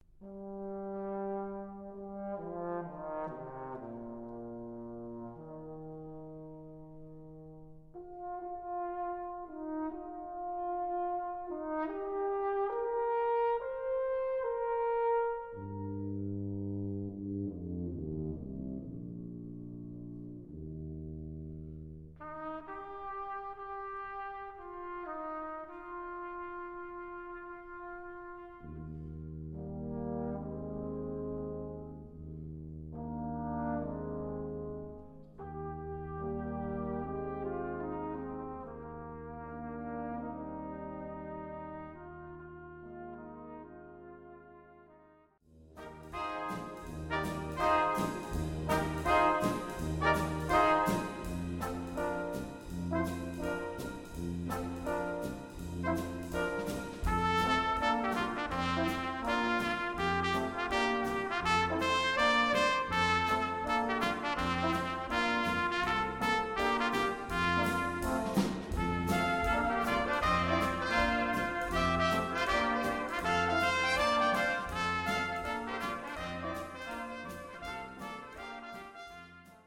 For Brass Quintet, Composed by Traditional.